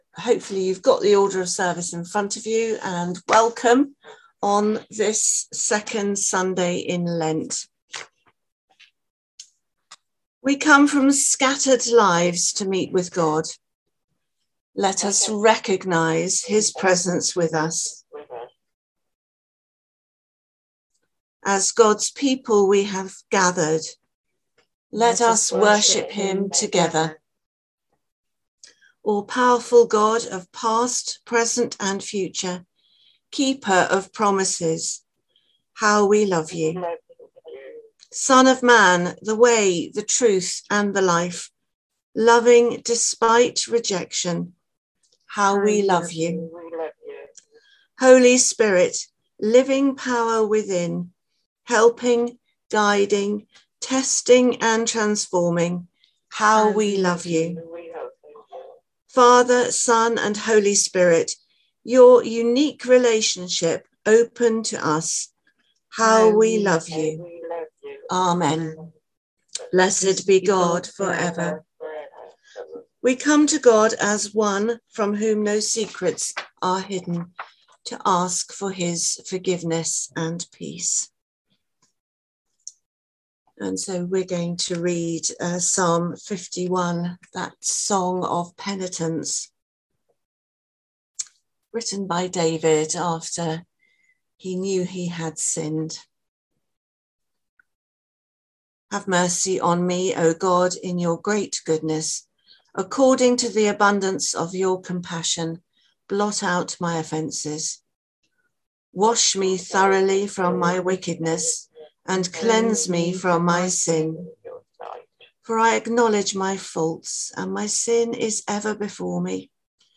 The audio from the Zoom / Conference Call service on Easter Sunday 17/04/2022.